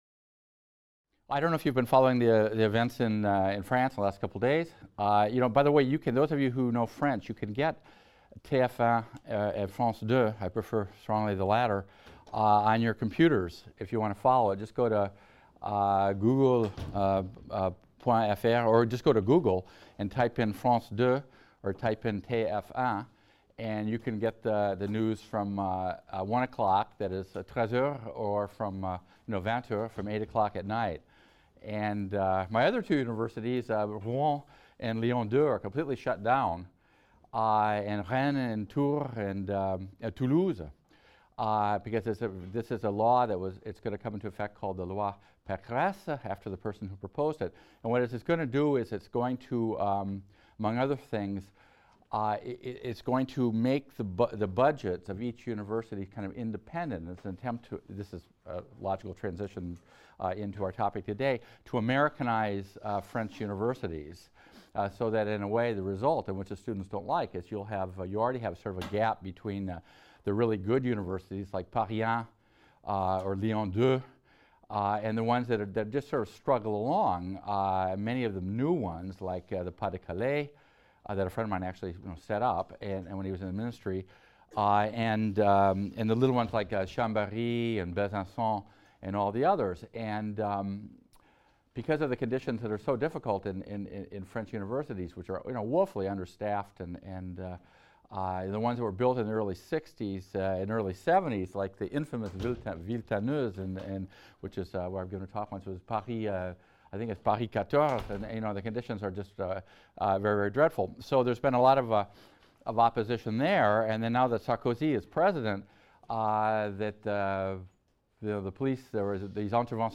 HIST 276 - Lecture 20 - Battles For and Against Americanization | Open Yale Courses